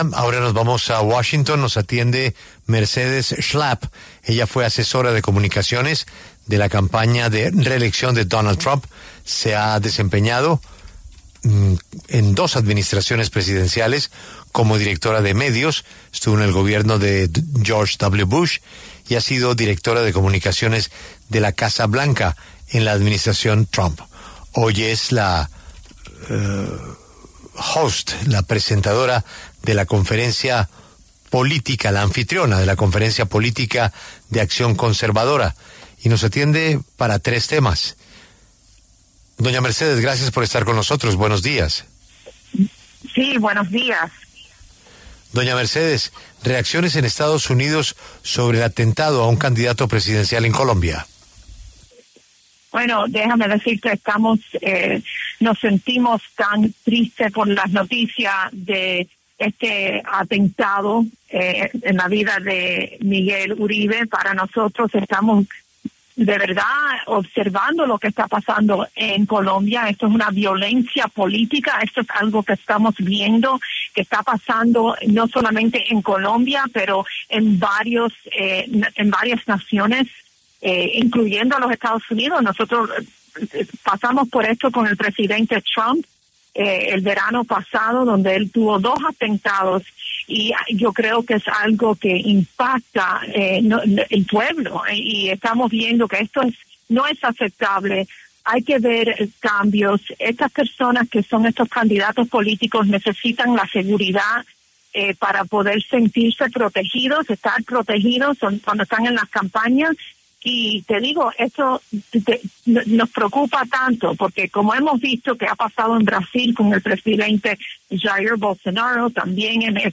Mercedes Schlapp, exasesora de comunicaciones de la campaña de reelección de Donald Trump en 2020, pasó por los micrófonos de La W, con Julio Sánchez Cristo, para hablar sobre el atentado contra el senador y precandidato presidencial Miguel Uribe en Bogotá.